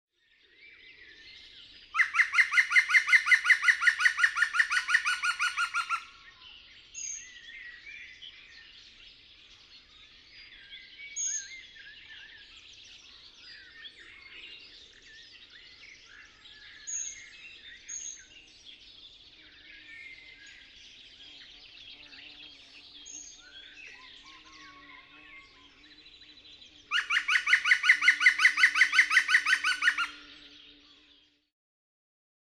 Groene specht
Groene-specht.mp3